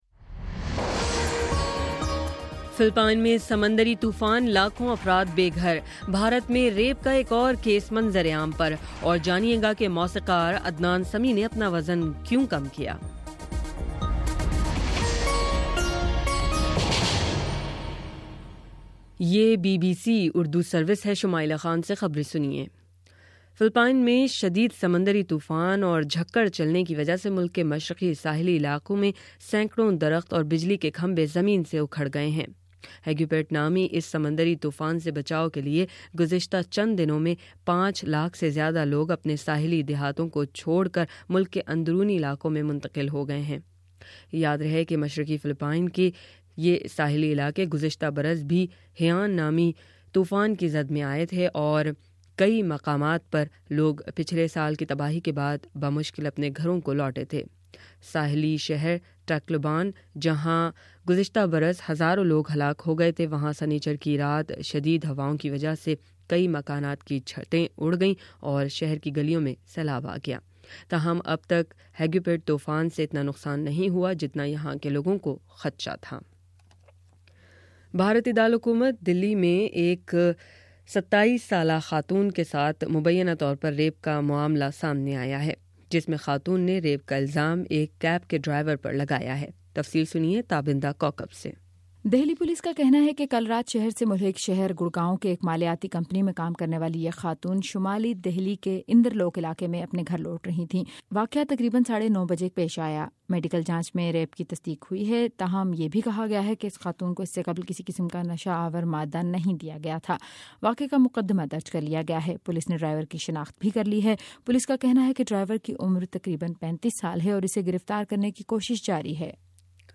دسمبر07: شام چھ بجے کا نیوز بُلیٹن